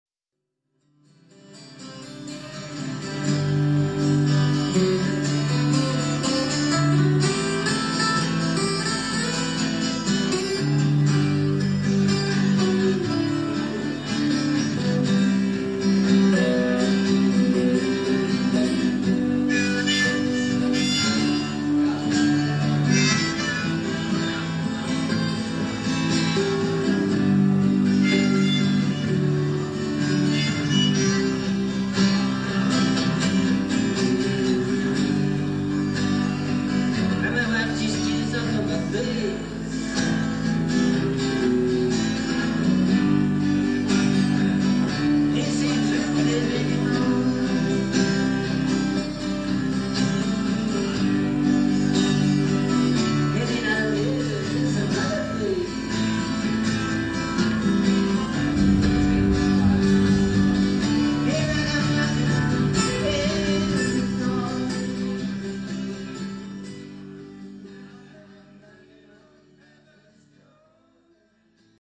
Malga Ime (Verona - Italy)